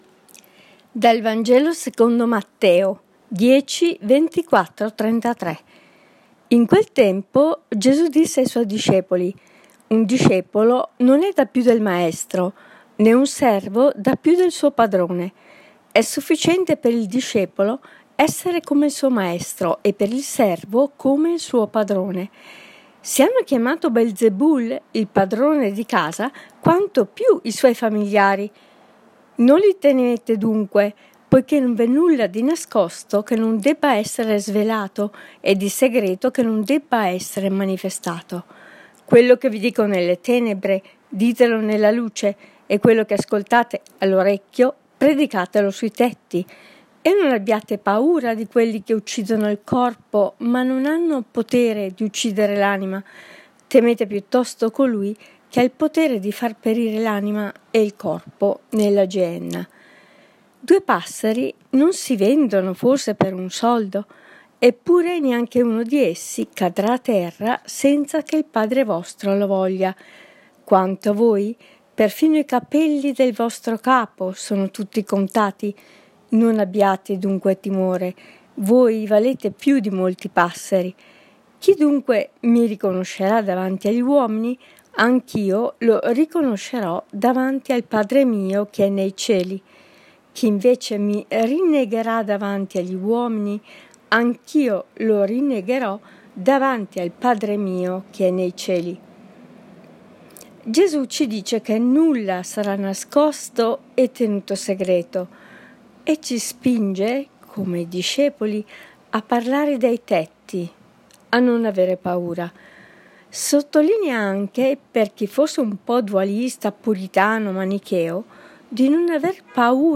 Commento al Vangelo (Mt 10,24-33) del 14 luglio 2018, sabato della XIV settimana del Tempo Ordinario, di una lettrice del blog.